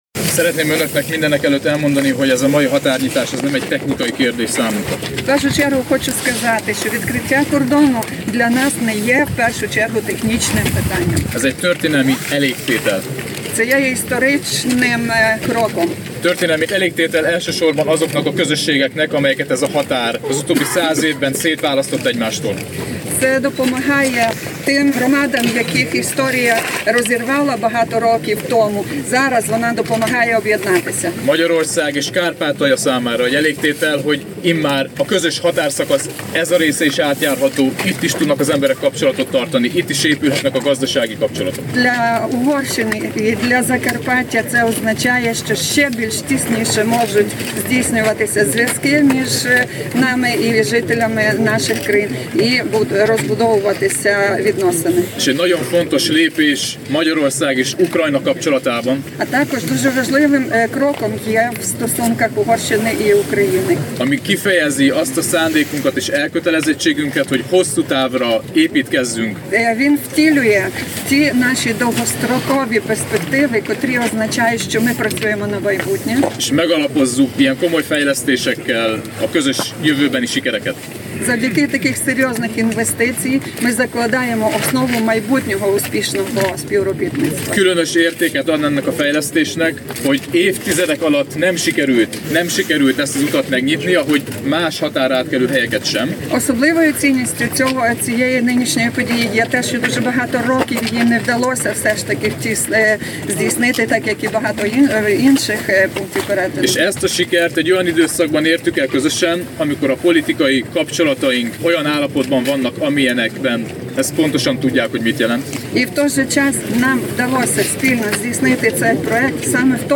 sajtotajekoztato_vagott.mp3